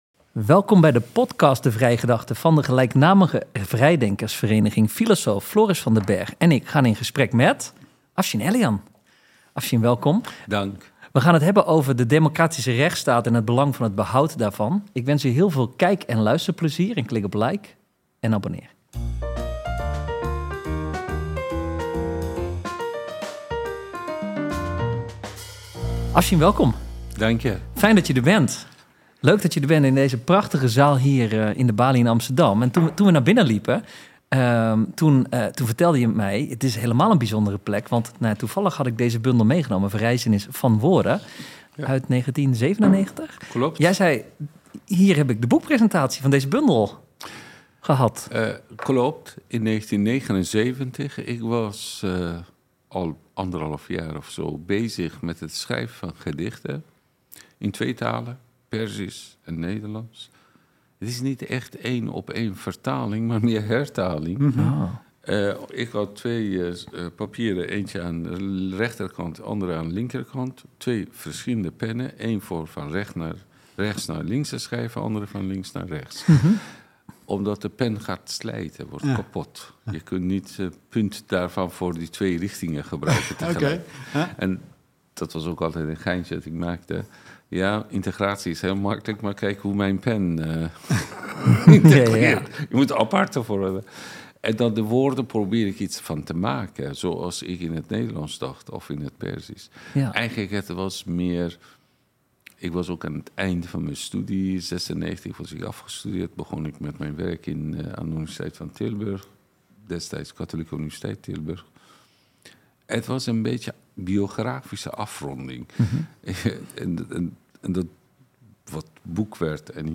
Deze podcasts zijn opgenomen met beeld.